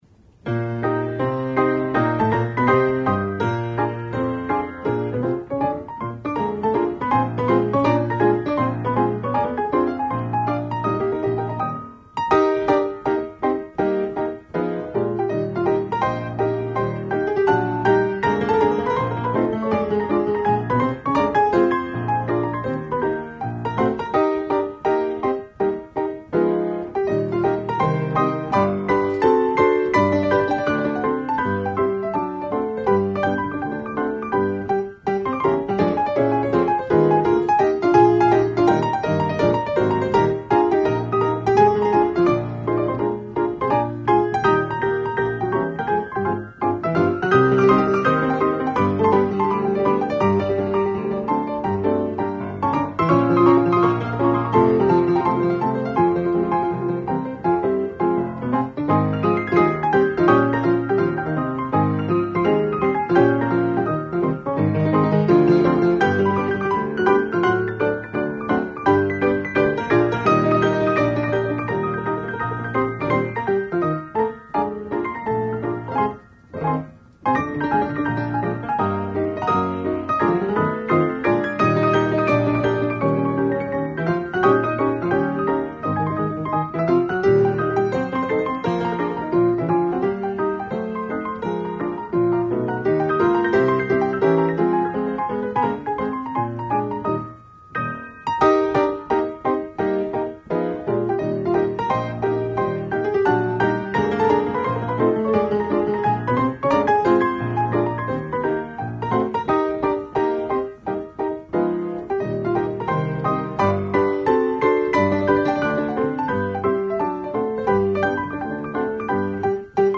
Reproducing Grand Piano
All selections are original Ampico rolls,